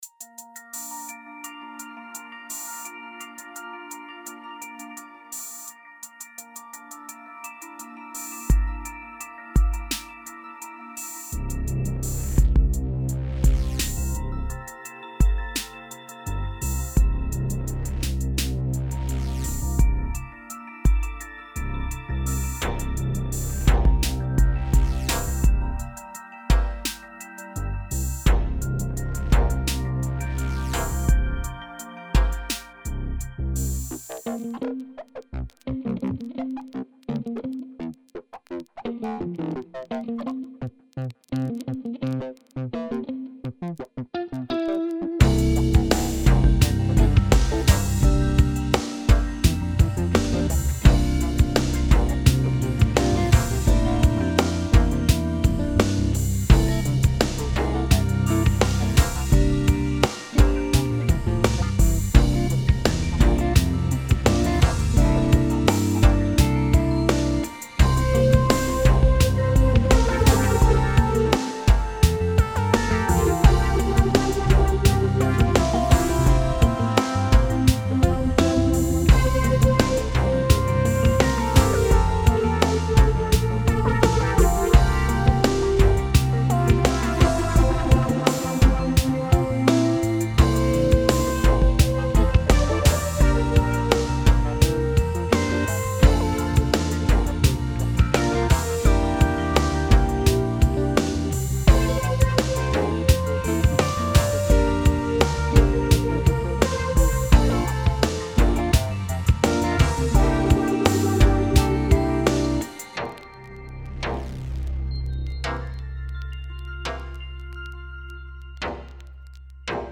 This song is mostly guitar synth. A very sleepy tune that might evoke the feeling of drifting off whilst driving along in the wee hours when you fall asleep in the left lane and awake in the right.